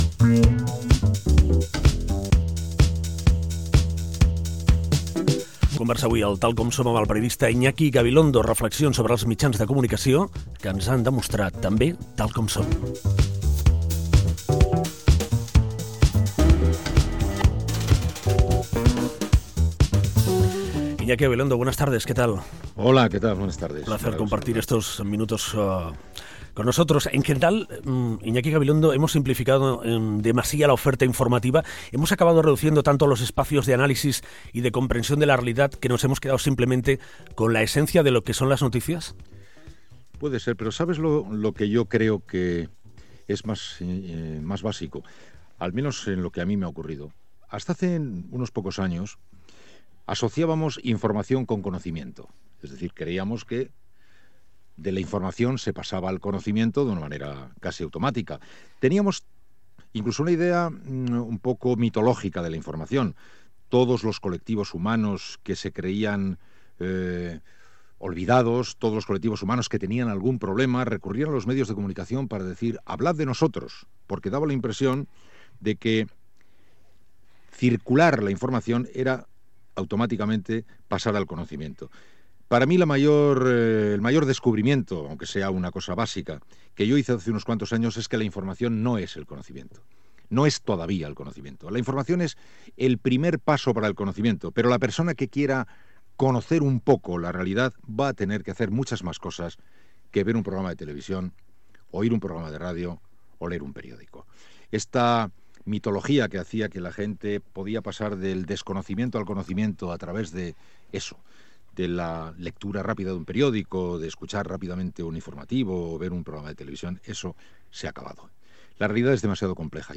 Entrevista a Iñaki Gabilondo qui parla de la informació periodística, els canvis als mitjans de comunicació, etc.